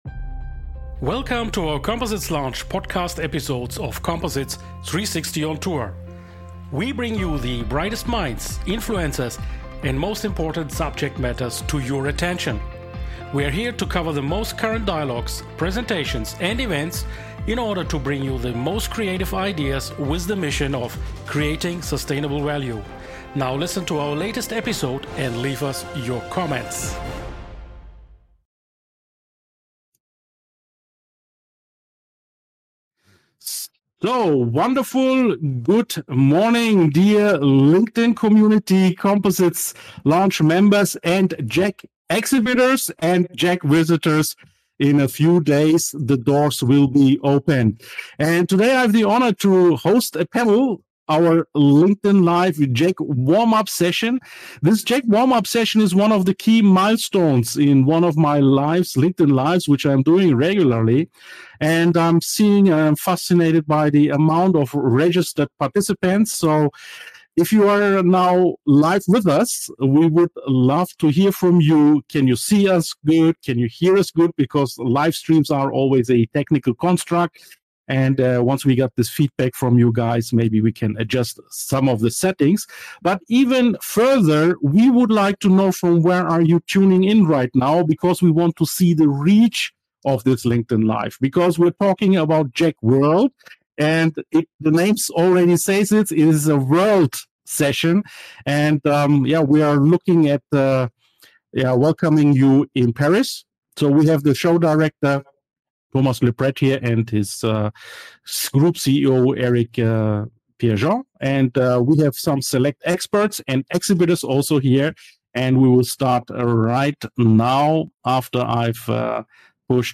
It would go far beyond this live talk to explain you everything, but we promise you to introduce you the support tools to make JEC World 2025 your networking event of the year. In the second part I have invited select exhibitors and composites experts and introduce them to you.